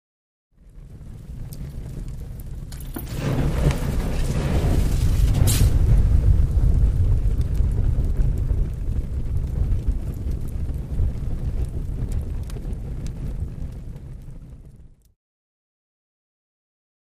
LighterFluidPoured HI026901
Lighter Fluid Poured on Fire